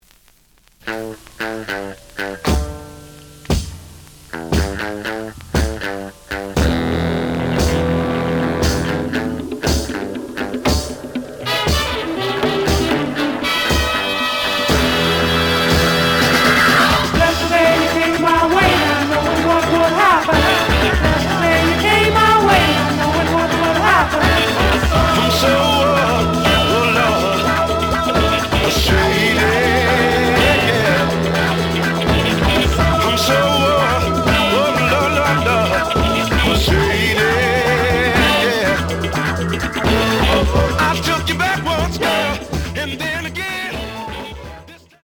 試聴は実際のレコードから録音しています。
The audio sample is recorded from the actual item.
●Genre: Funk, 70's Funk